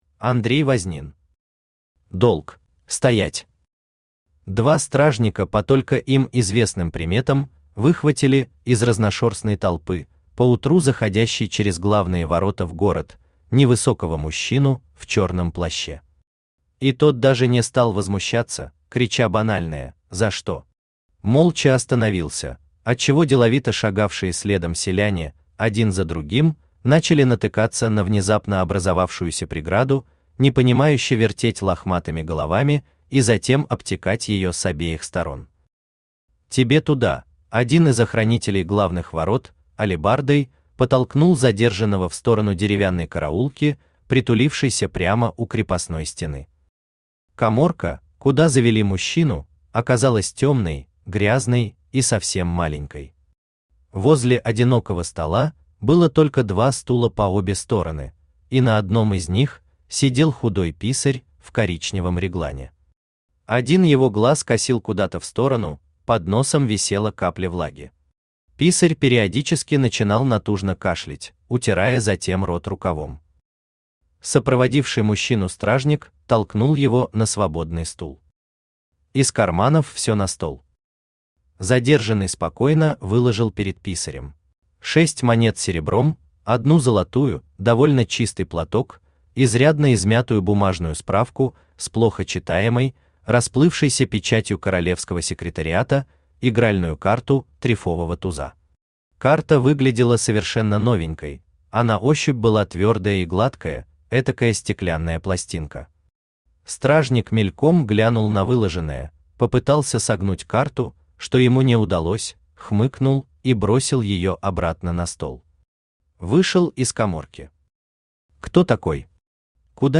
Аудиокнига Долг | Библиотека аудиокниг
Aудиокнига Долг Автор Андрей Андреевич Вознин Читает аудиокнигу Авточтец ЛитРес.